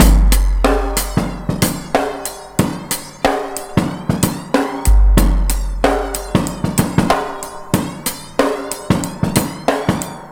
Index of /90_sSampleCDs/Best Service ProSamples vol.24 - Breakbeat [AKAI] 1CD/Partition B/ONE HAND 093